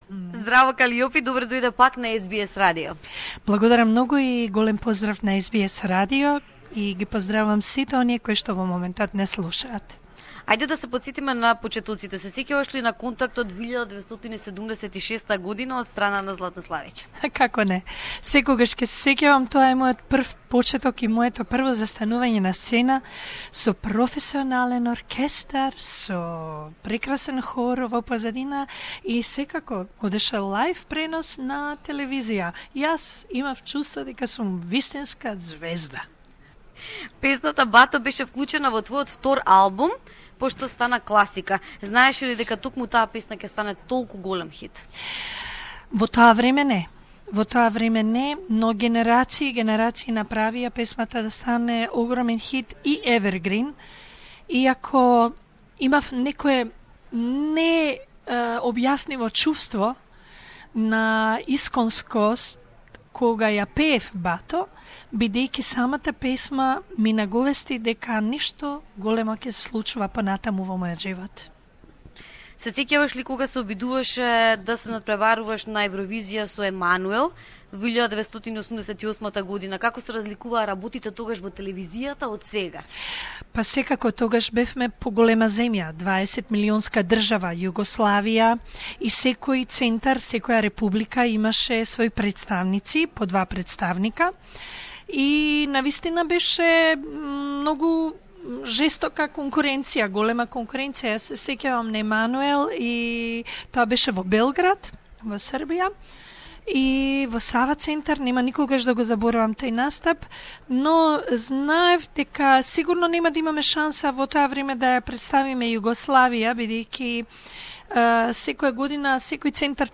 Exclusive SBS interview with Kaliopi who is representing Macedonia at The Eurovision Song Contest 2016 in Stockholm. Hear the diva's greetings to her Australian-Macedonian fans here!